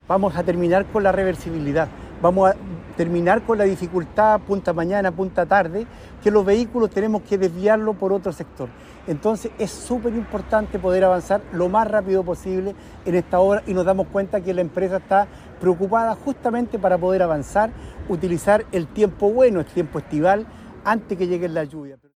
Con esto mejorarán los tiempos de viaje entre Concepción y Chiguayante, y terminará la reversibilidad que hay en la Avenida Costanera, recordó el alcalde la comuna, Jorge Lozano.